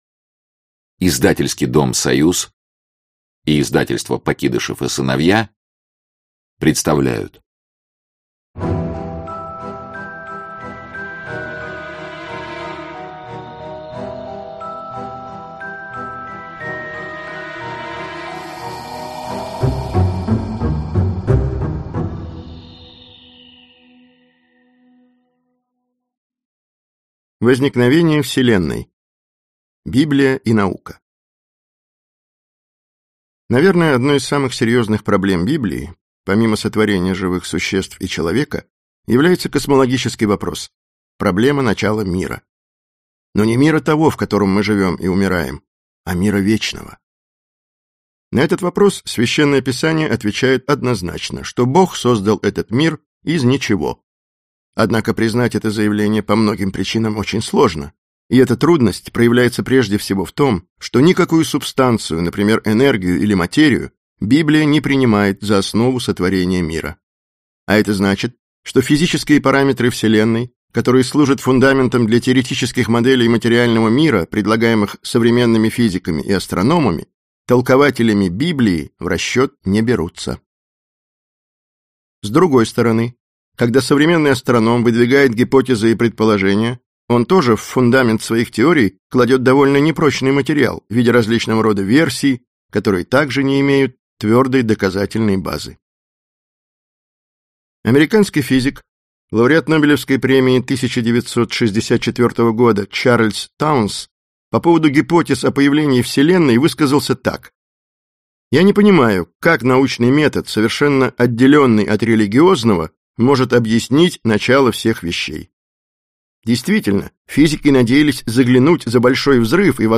Аудиокнига Тайны Библии | Библиотека аудиокниг